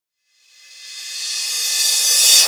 Rev_Crsh.wav